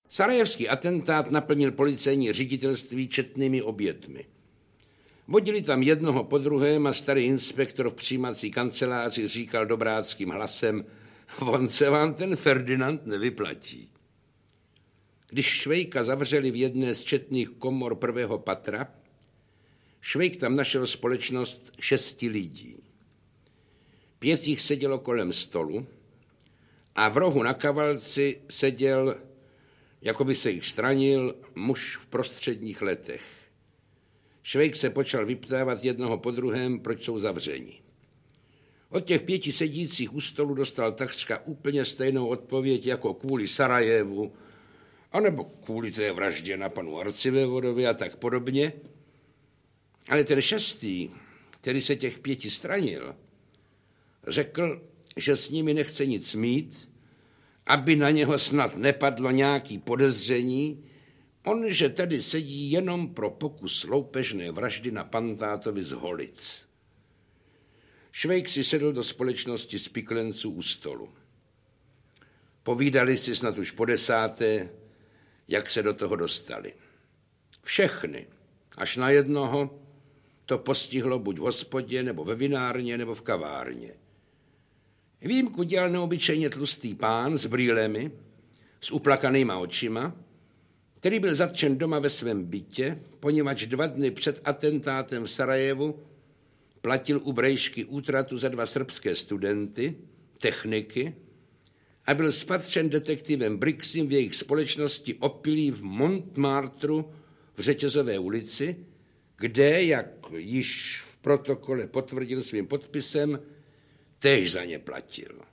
Audio kniha
Ukázka z knihy
Dokonalému přednesu Jana Wericha úspěšně sekundují Jiřina Šejbalová (paní Müllerová) a Vlasta Burian (feldkurátKatz). Nahrávky z let 1953-1966 patří mezi klenoty archivu SUPRAPHONu!Obsah: 1.
• InterpretJan Werich, Vlasta Burian, Jiřina Šejbalová, František Filipovský